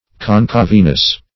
Concaveness \Con"cave*ness\, n.
concaveness.mp3